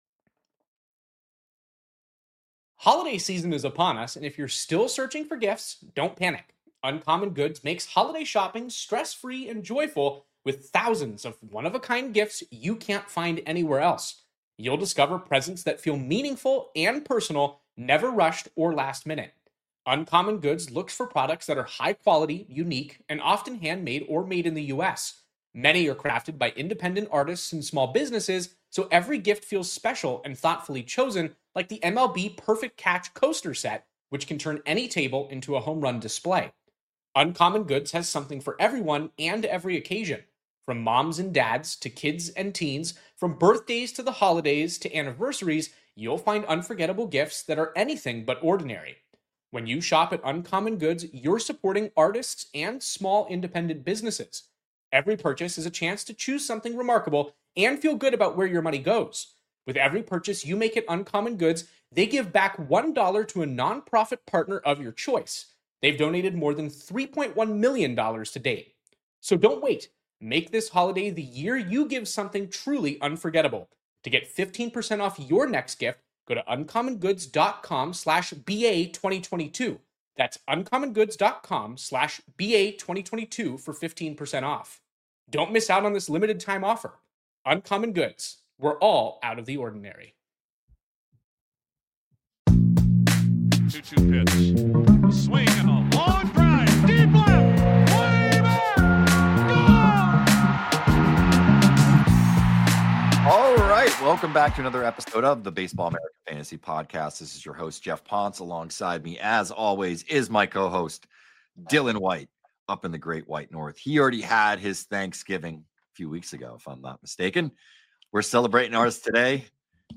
We follow with a discussion of the Sonny Gray trade and al the players moved in that deal. The majority of the show dives into our recently released second baseman rankings for 2026 dynasty baseball.